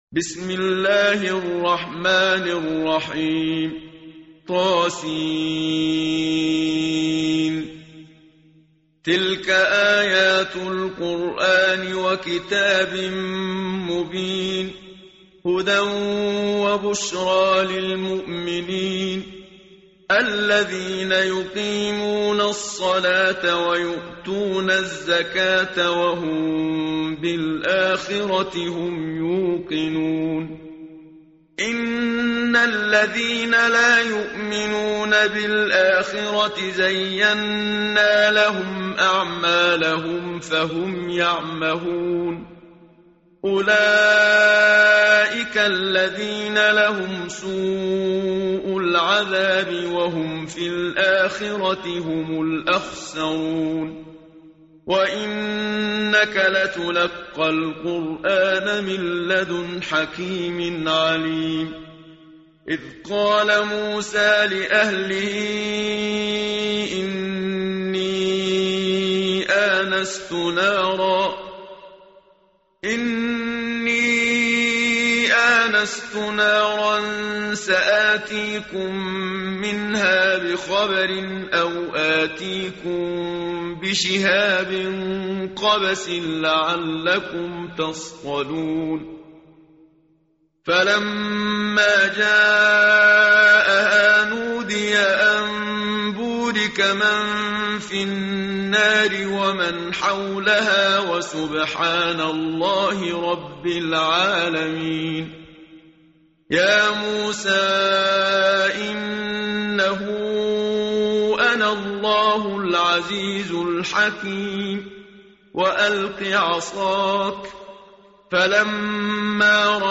متن قرآن همراه باتلاوت قرآن و ترجمه
tartil_menshavi_page_377.mp3